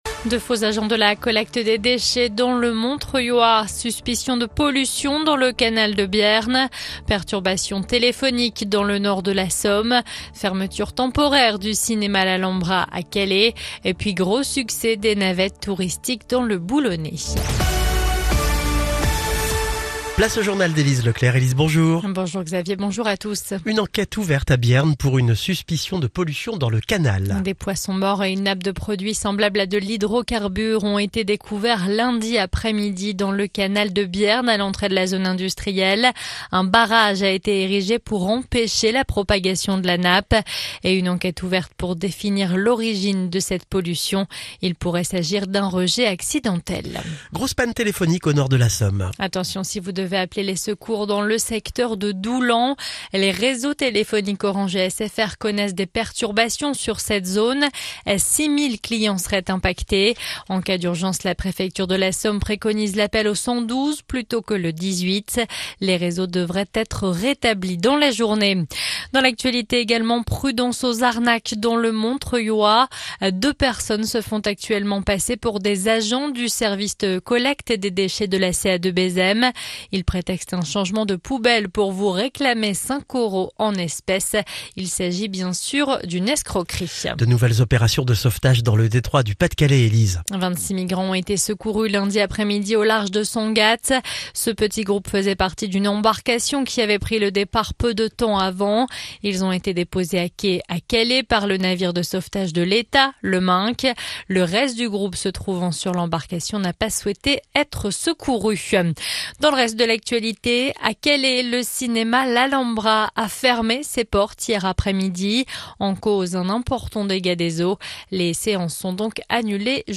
(journal de 9h)